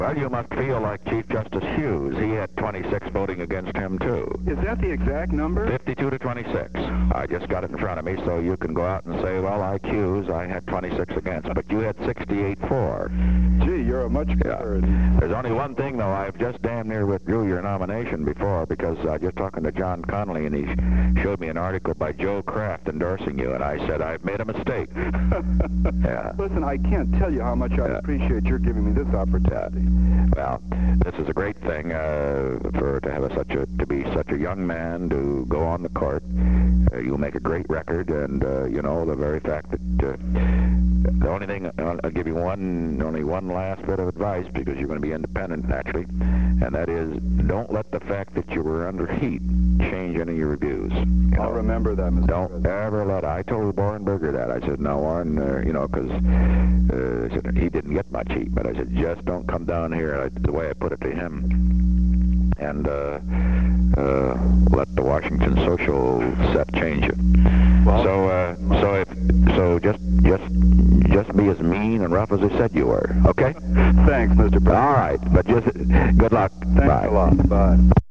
Date: December 10, 1971 Location: White House Telephone Tape Number: 016-086 Participants Richard M. Nixon William H. Rehnquist Associated Resources Audio File Transcript